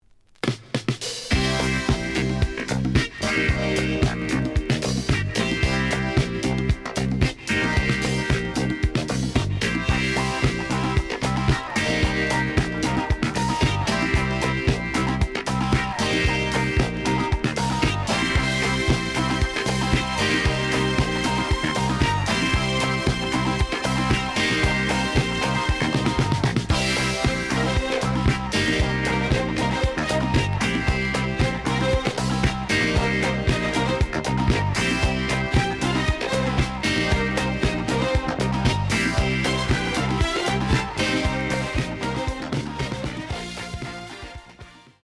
The audio sample is recorded from the actual item.
●Genre: Disco
Slight edge warp.